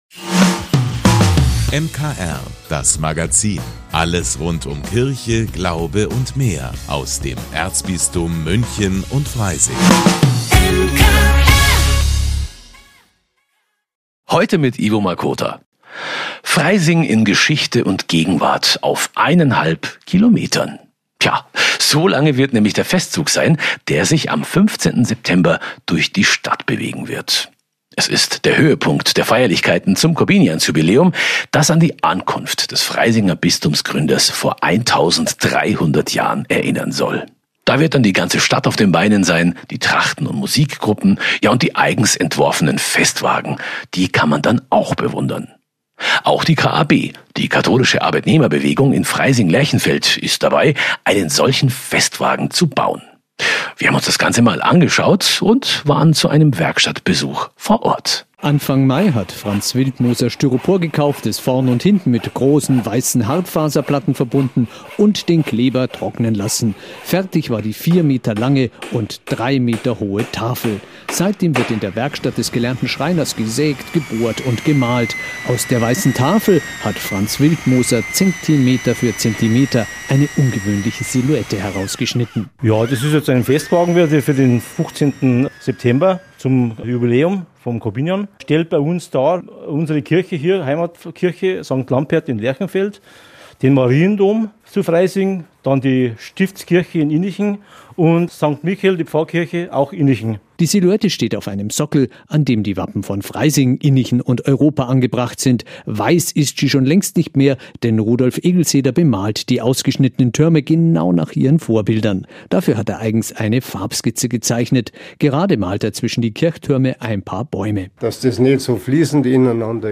In einer Werkstatt erhält der Wagen seinen letzten Schliff, das MKR war vor Ort. Im Kitaradio geht es diese Woche um Lösungsstrategien, die Kinder schon jung lernen und die man das ganze Leben nutzt.